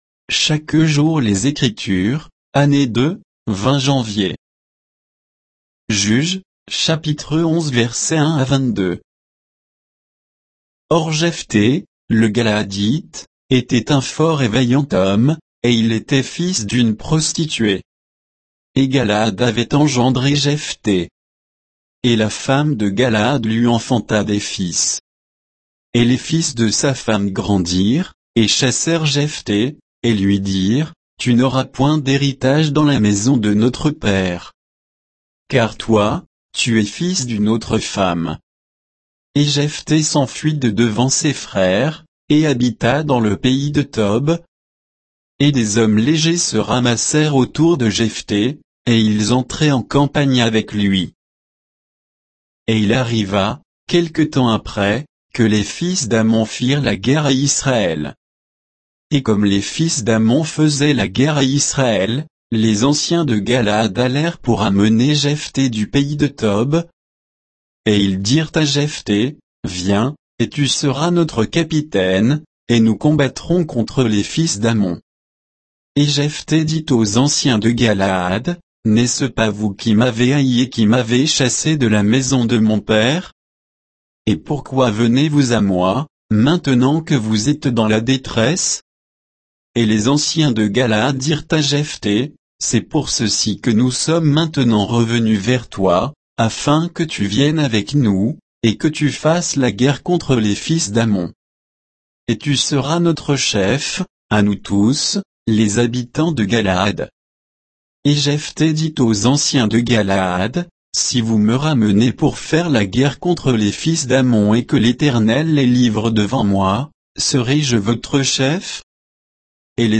Méditation quoditienne de Chaque jour les Écritures sur Juges 11, 1 à 22